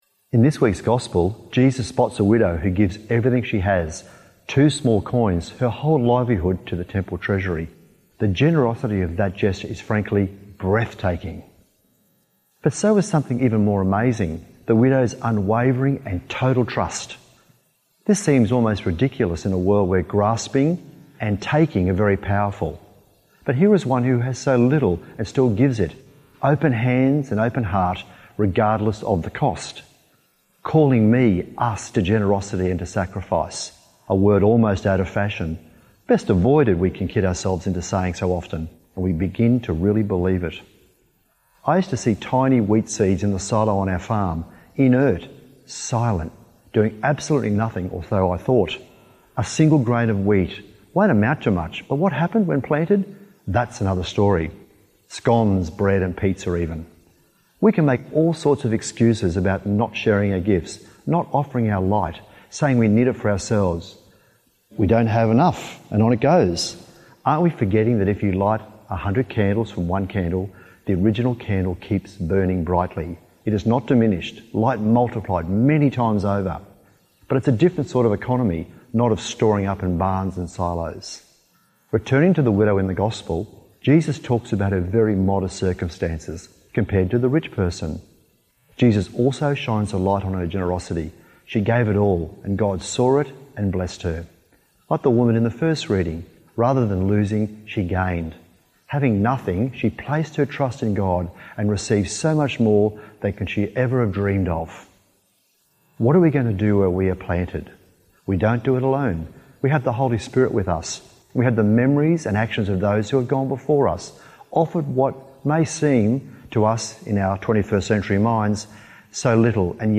Archdiocese of Brisbane Thirty-Second Sunday in Ordinary Time - Two-Minute Homily